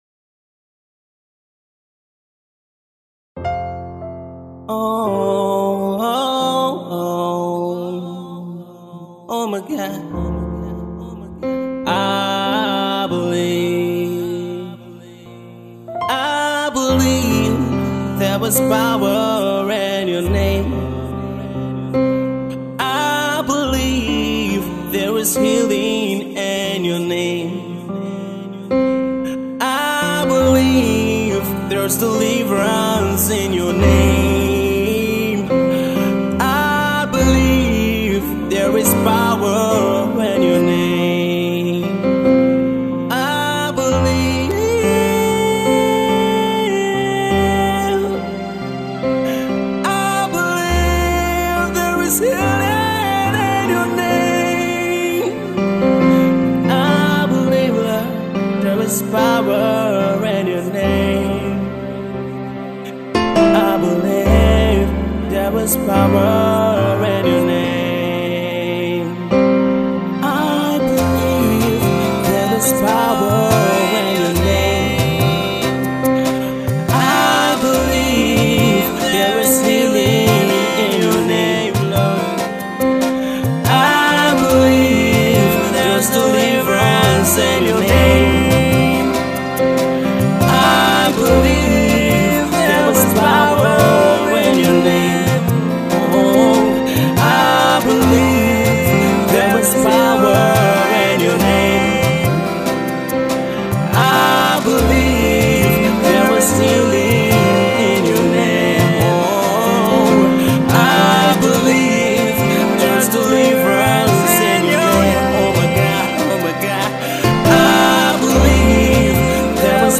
Gospel worshiper
worship tune